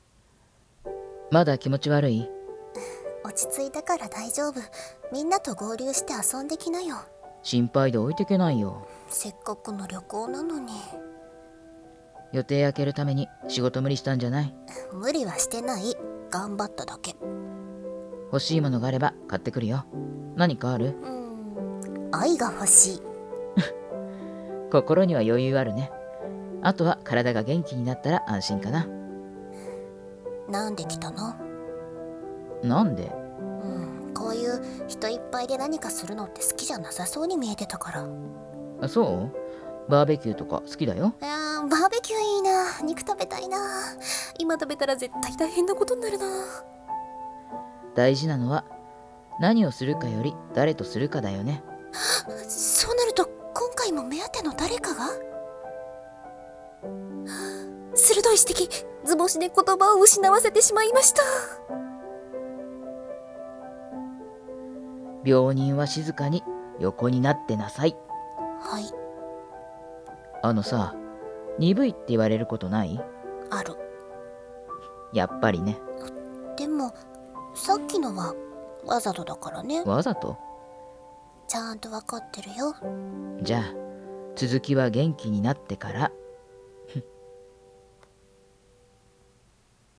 元気に、なってから。【二人声劇】 演◆